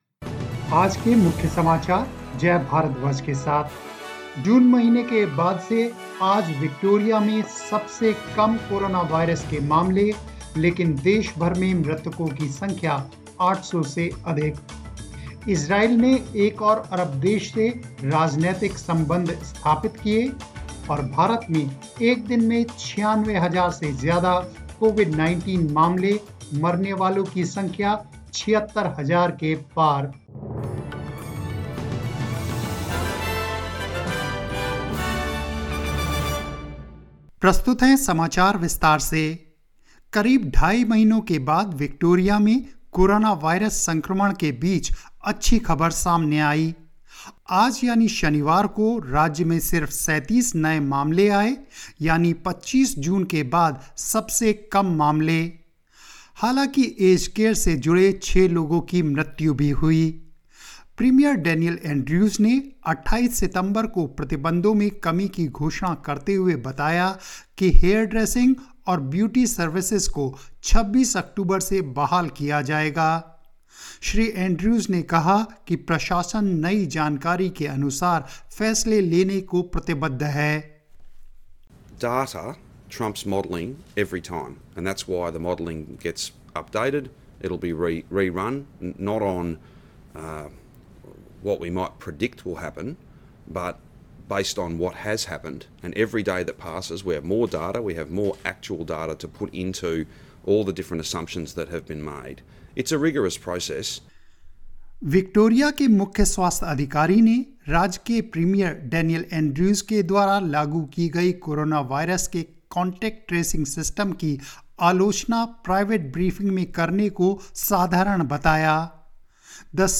News in Hindi 12 September 2020